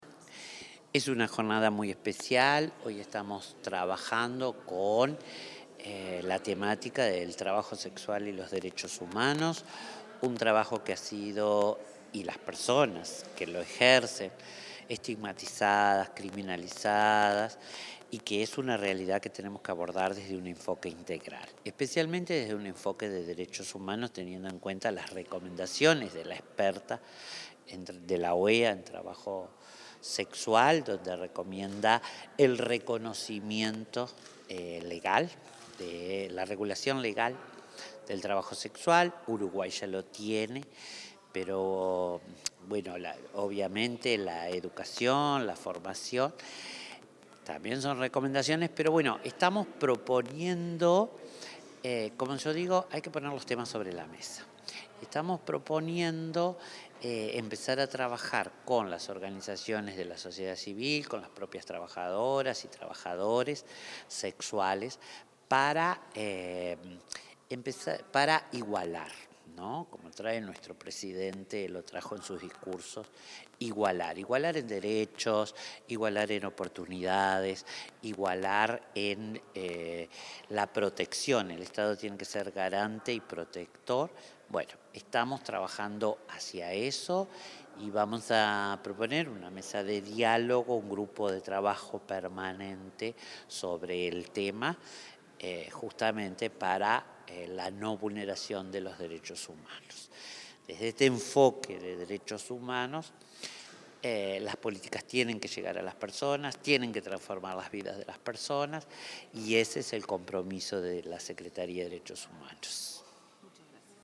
Declaraciones de la secretaria de Derechos Humanos, Collette Spinetti
Declaraciones de la secretaria de Derechos Humanos, Collette Spinetti 22/07/2025 Compartir Facebook X Copiar enlace WhatsApp LinkedIn Tras finalizar la mesa Trabajo Sexual y Derechos Humanos, organizada por la Secretaría de Derechos Humanos de Presidencia, la titular de esa repartición, Collette Spinetti, dialogó con la prensa.